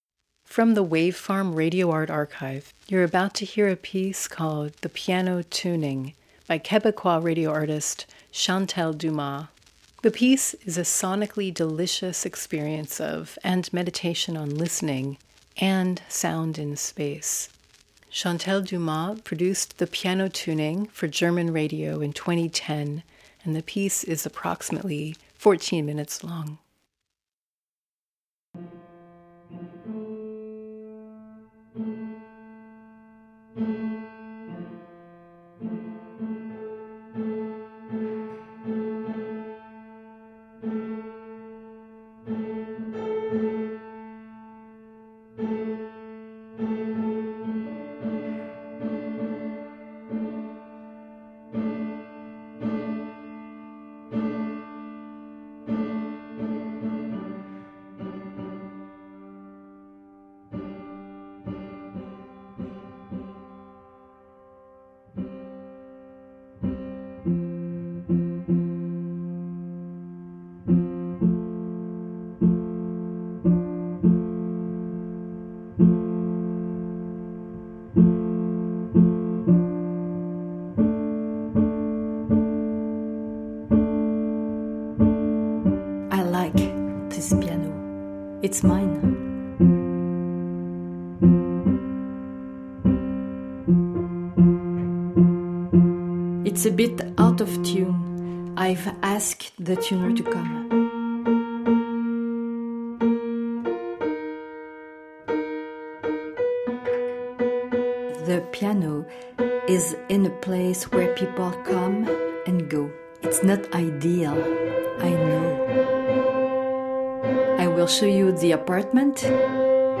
a sonically delicious experience of and meditation on listening and sound in space